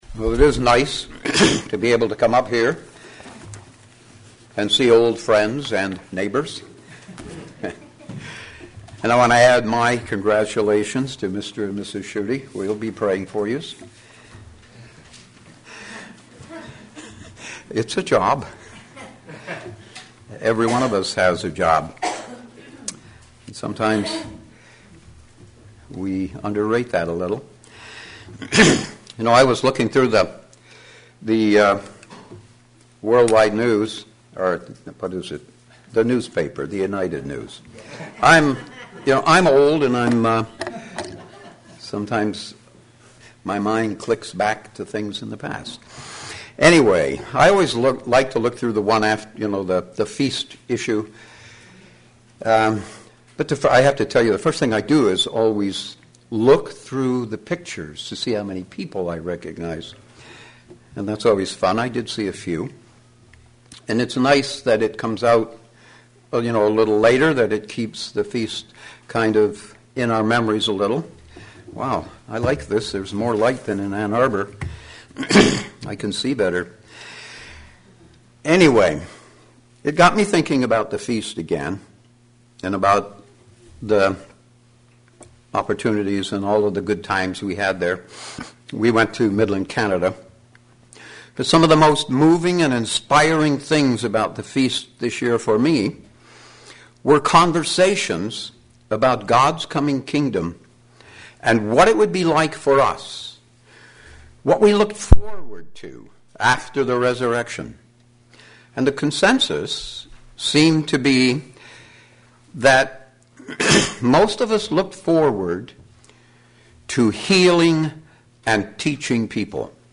Will we be called to heal the people? sermon Studying the bible?